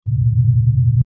Alien Spaceship Hum 04
Alien_spaceship_hum_04.mp3